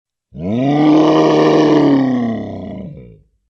Скачивайте рыки, рев, тяжелое дыхание и крики фантастических существ в формате MP3.
Рык огромного злобного мутанта